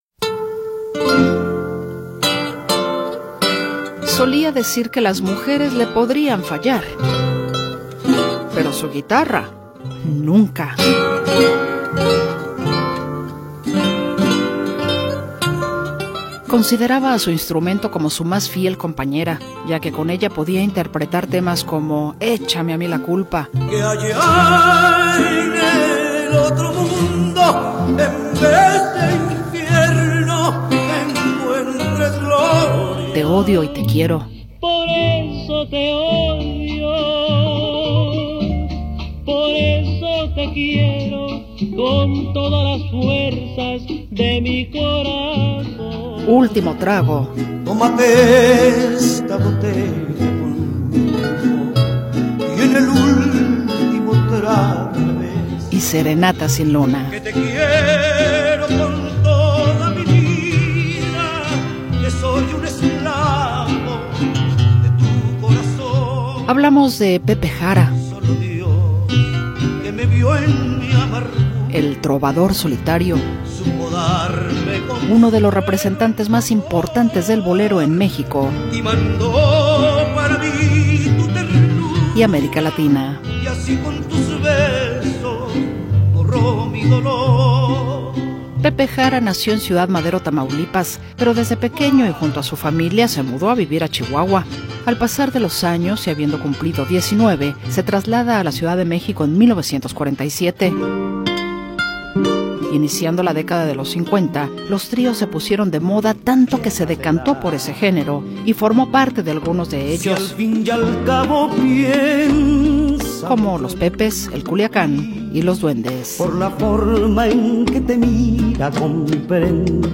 música ranchera y de bolero
Con una voz cálida y estilo emotivo
Un crooner con sello nostálgico.